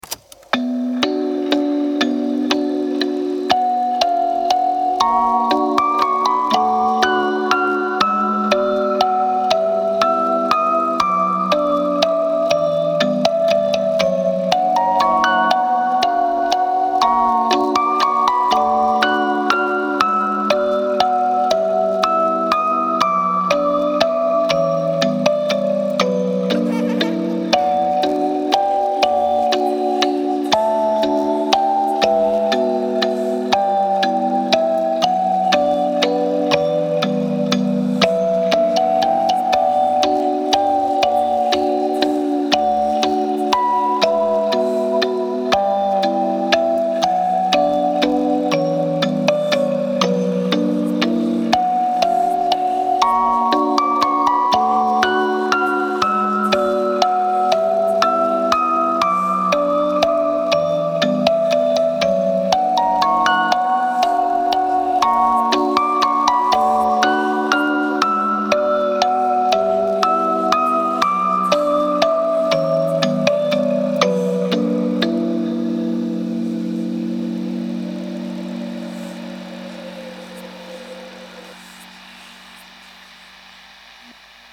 Piano Instrumental
Без слов